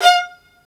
Index of /90_sSampleCDs/Roland L-CD702/VOL-1/STR_Vlns Marc1-3/STR_Vls Marc3 x6